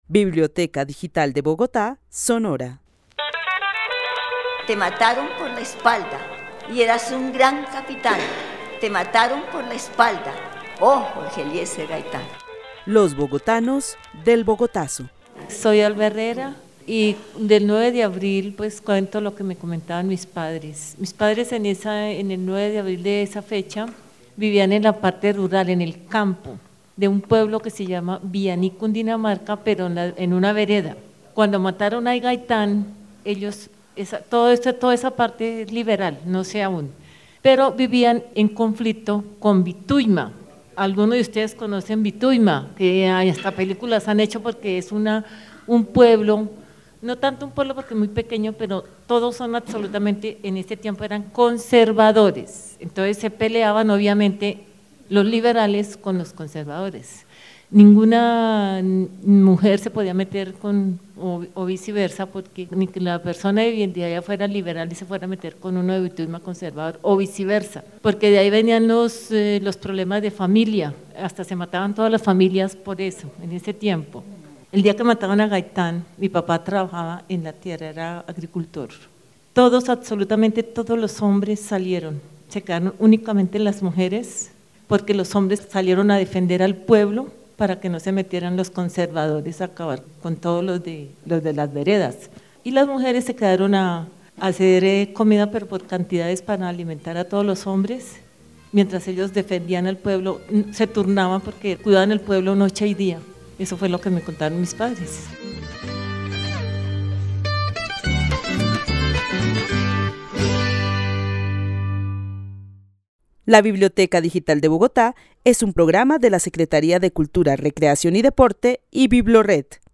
Memoria colectiva, Insurrección del 9 de abril de 1948, Gaitán, Jorge Eliécer, 1898-1948 - Asesinato, Historia